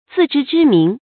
zì zhī zhī míng
自知之明发音